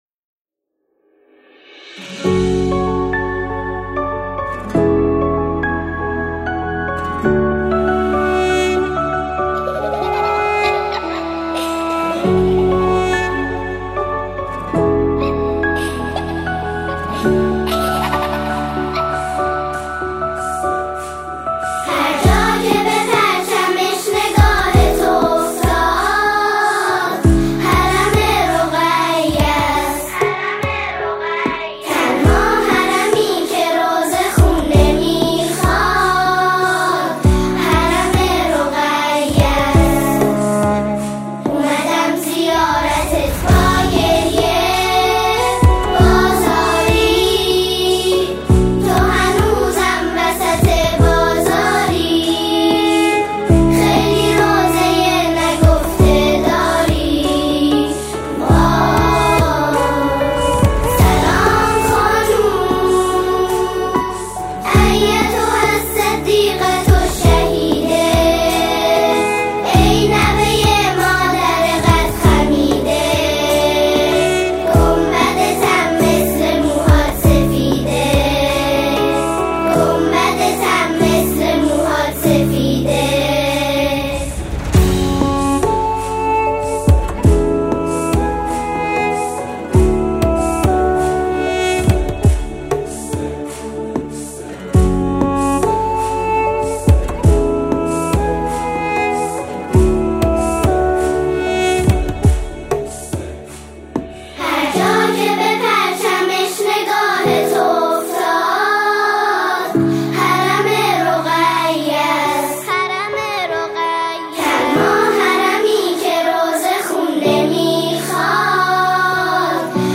با لحنی حزن‌انگیز و جمع‌خوانی نوجوانان گروه
ژانر: سرود